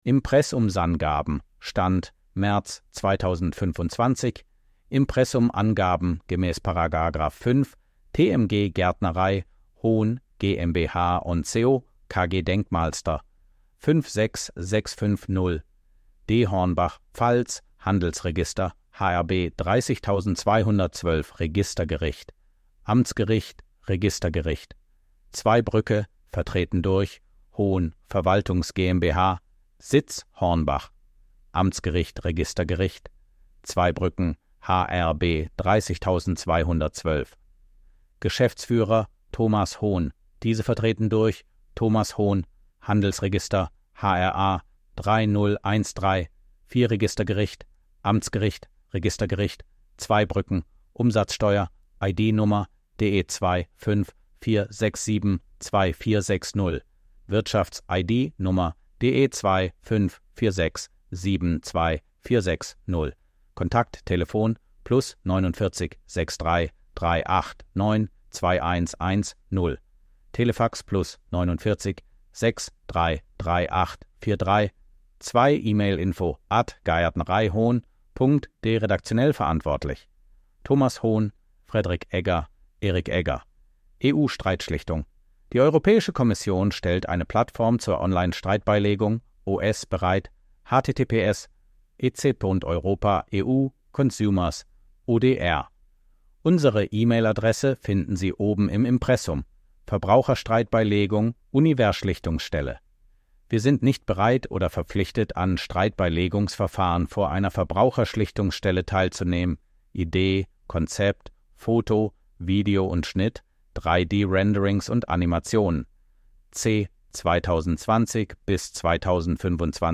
Audio-Datei mit vorgelesenem Text der Impressumsangaben der Gärtnerei Hohn.